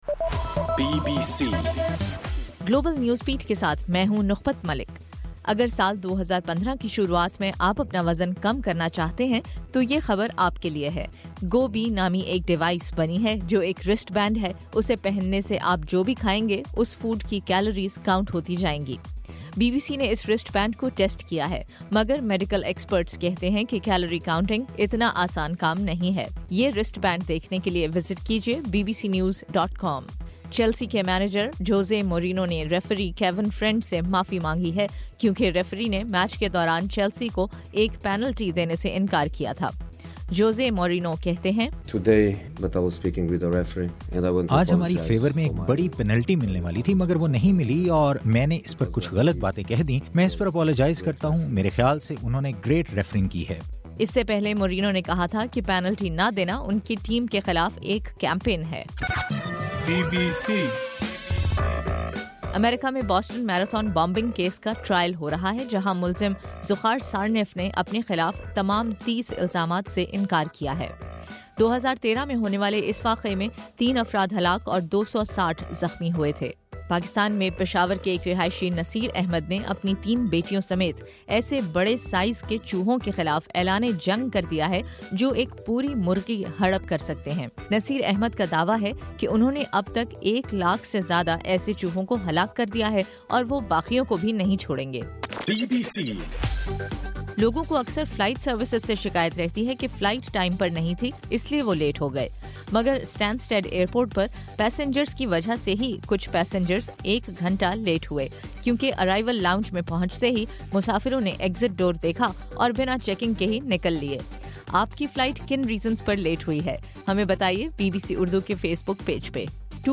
جنوری 6: صبح 1 بجے کا گلوبل نیوز بیٹ بُلیٹن